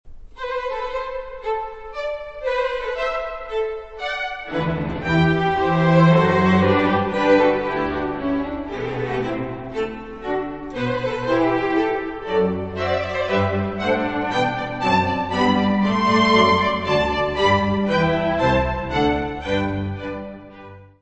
Music Category/Genre:  Classical Music
Andante grazioso.